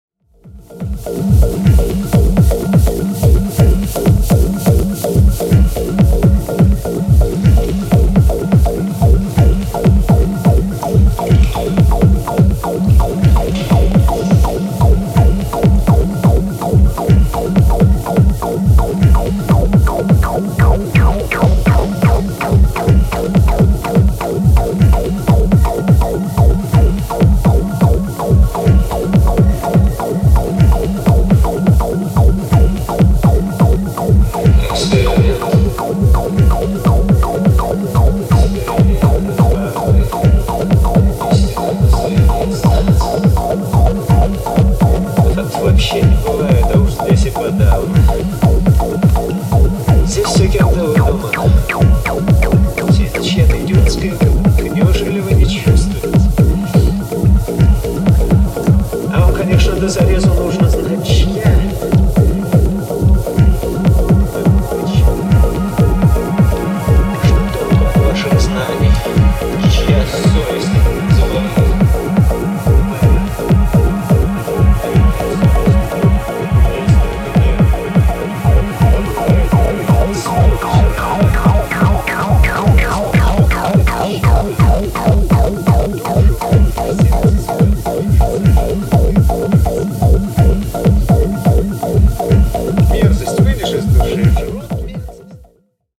House Techno